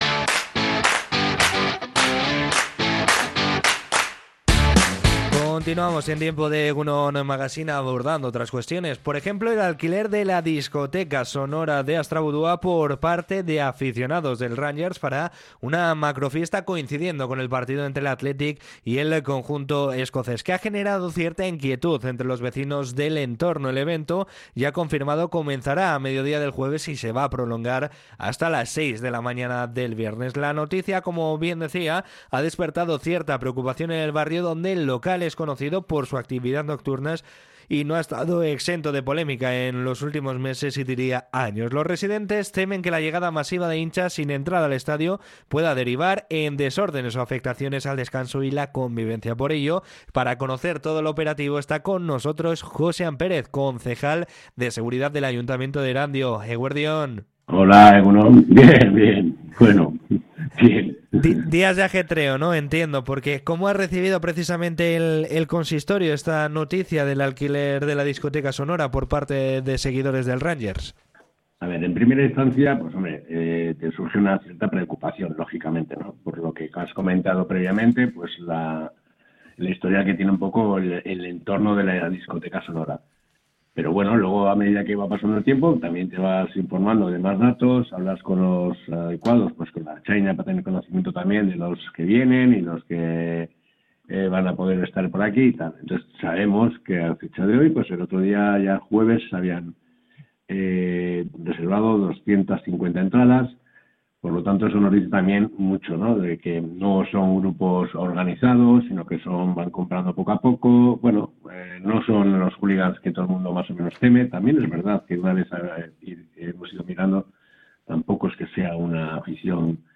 Josean Pérez, concejal de Seguridad de Erandio, ha explicado en Radio Popular - Herri Irratia las medidas previstas en Astrabudua